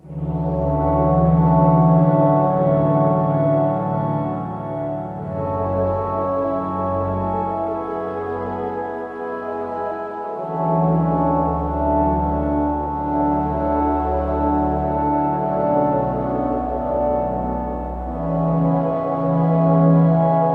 LOOP - WAIST TRAINER.wav